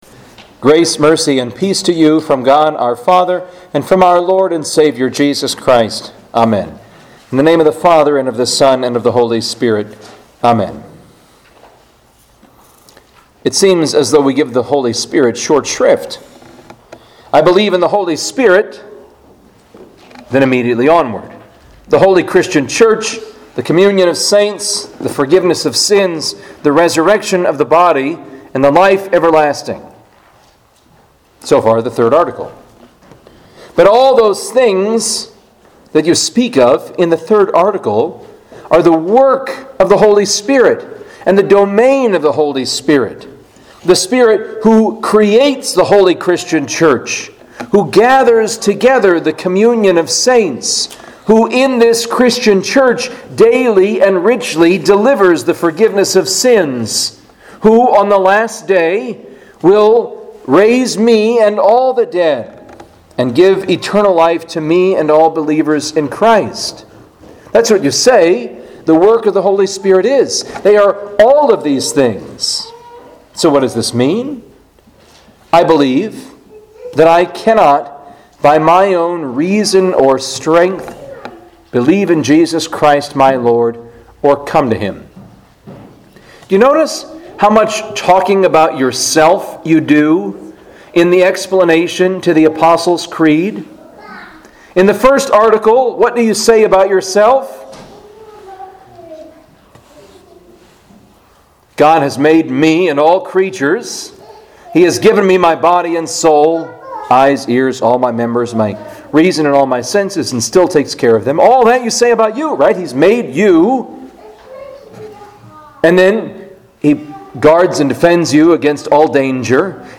Home › Sermons › Laetare Wednesday